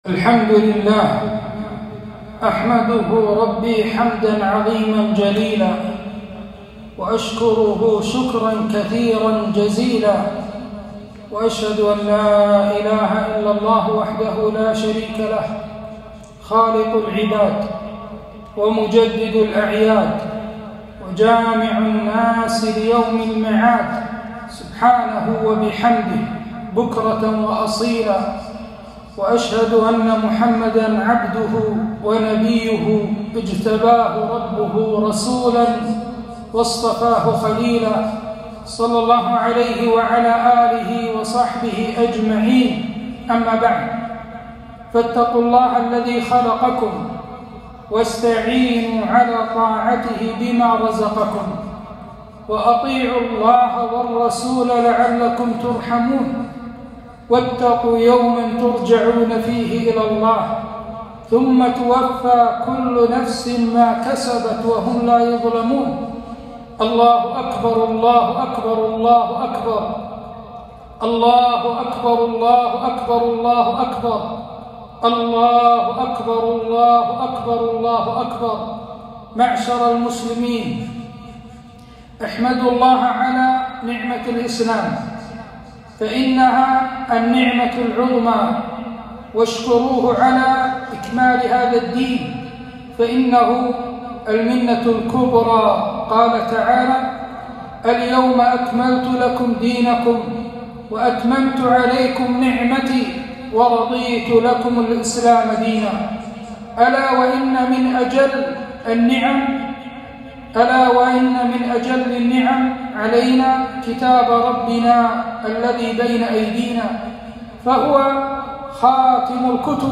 عيدنا أهل الإسلام (خطبة عيدالأضحى) 1442